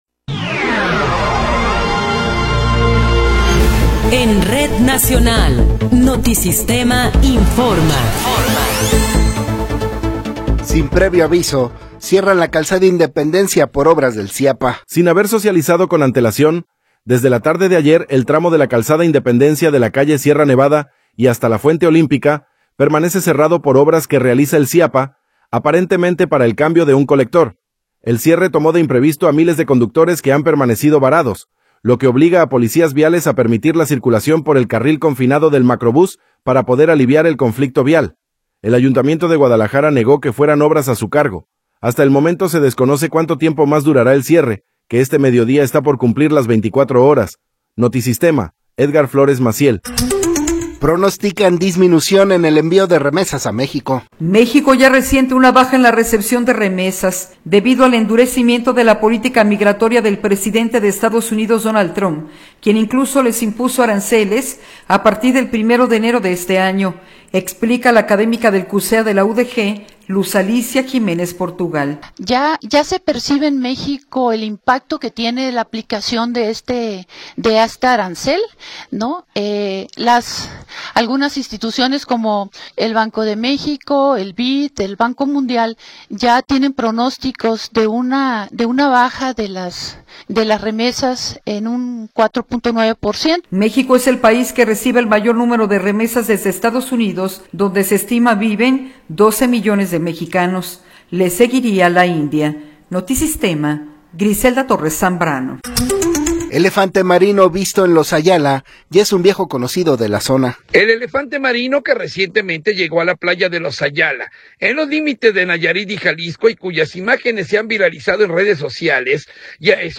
Noticiero 13 hrs. – 29 de Enero de 2026
Resumen informativo Notisistema, la mejor y más completa información cada hora en la hora.